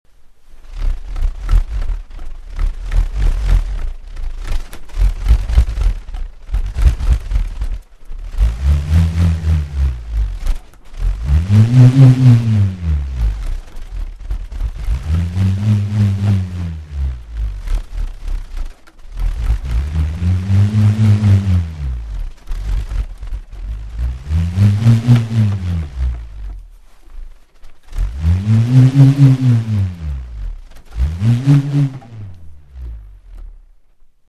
L’air ambiant mis en mouvement par cette double rotation de l’objet aérodynamique provoque un bruit de ronflement.
Le rhombe